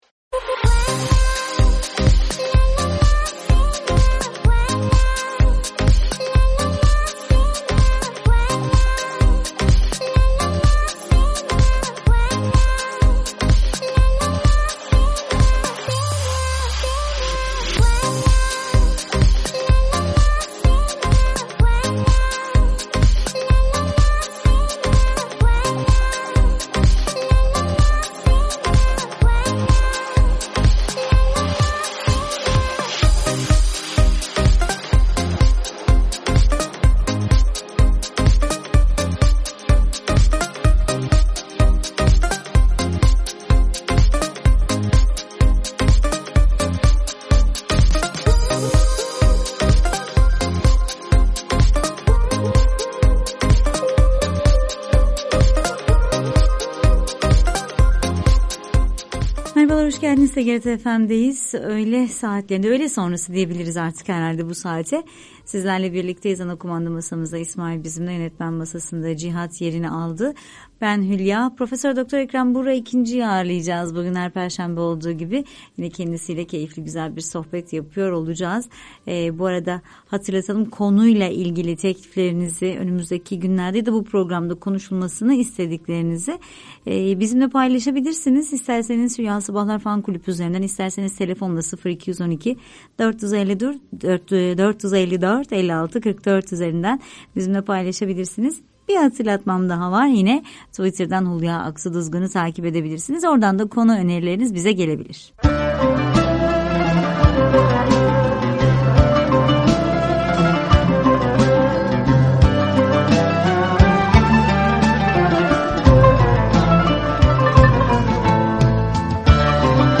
Radyo Programi - Eskiden ticaret ahlakı nasıl temin edilirdi?